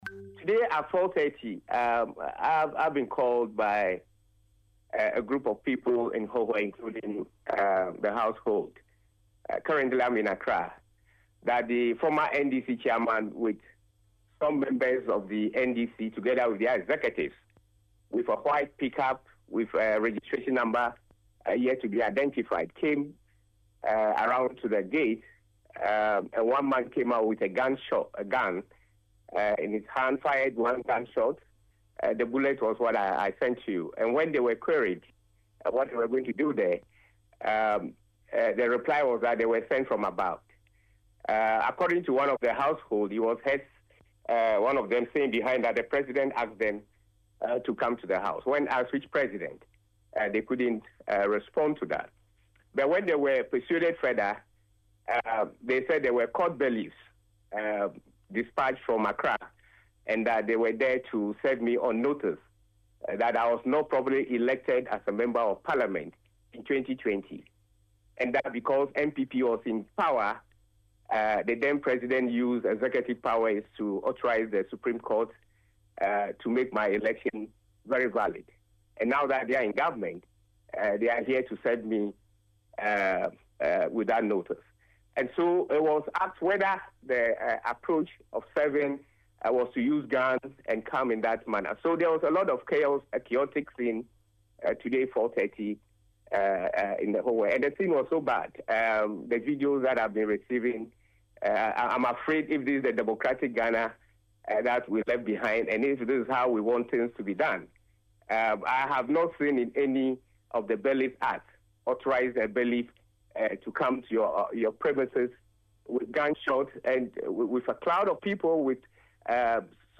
The former Minister for Railways Development confirmed the attack in an interview with Citi FM, stating that the assailants were armed.